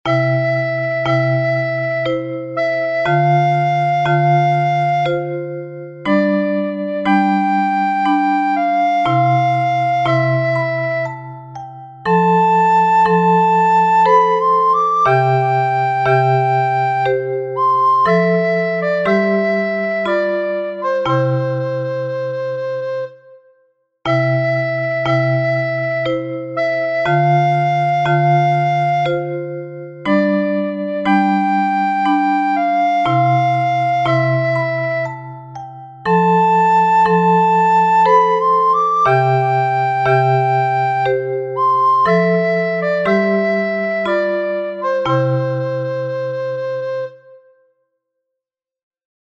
A tempo: